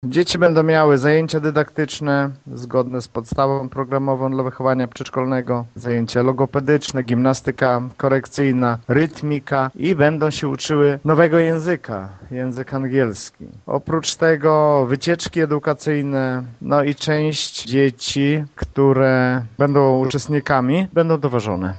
Pieniądze na ich przygotowanie samorząd pozyskał z Europejskiego Funduszu Społecznego - mówi wójt Gminy Mircze Lech Szopiński: